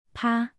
(pā) — bang